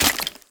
1.21.5 / assets / minecraft / sounds / mob / bogged / step1.ogg
step1.ogg